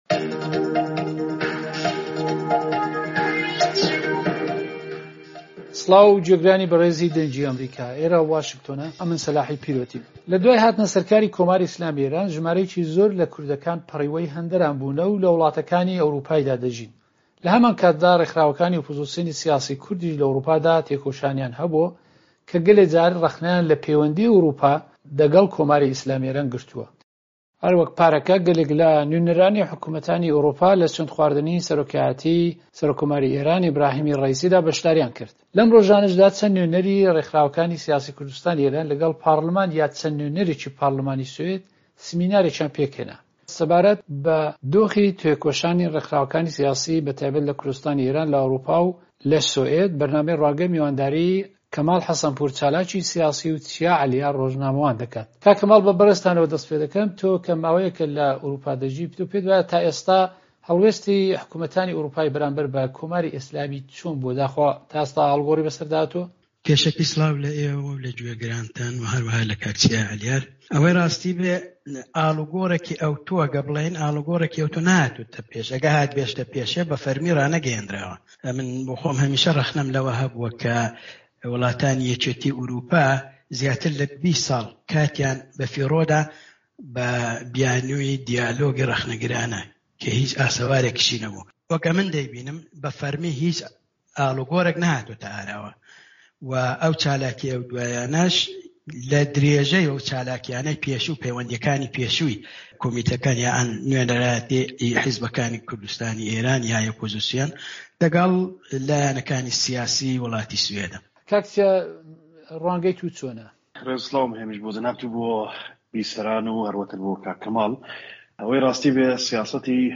چەند ڕۆژ لەمەوپێش سمینارێک لە پەرلمانی سوید پێک هات کە نوێنەرانی پارتەکانی کورد تێیدا بەشدار بوون. سەبارەت بە دۆخی تێکوشانی سیاسی لە ئەوروپا و ئایا کام لایەنی ئەوروپایی زیاتر لایەنگری لە مەسەلەی کورد لە کوردستانی ئێران دەکەن، بەرنامەی ڕوانگە میوانداری دوو بەشداربووی ئەم سمینارەی کرد